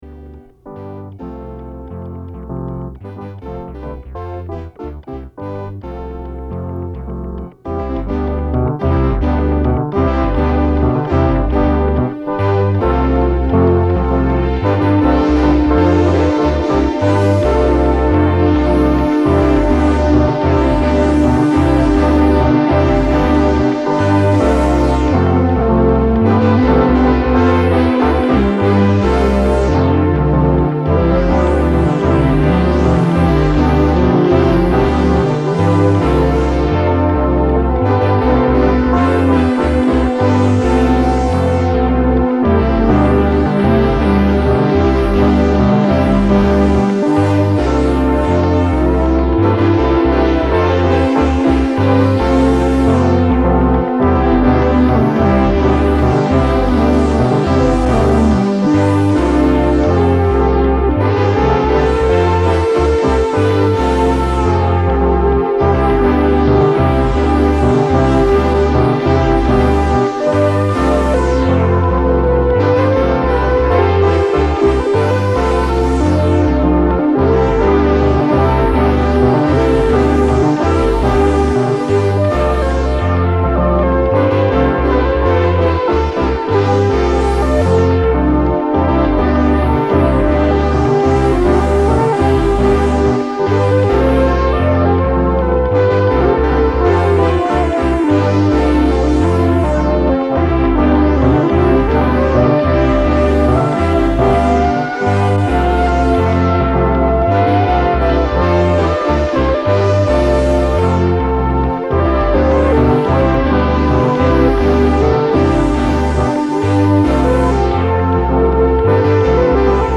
Analog recording using Fostex X-15.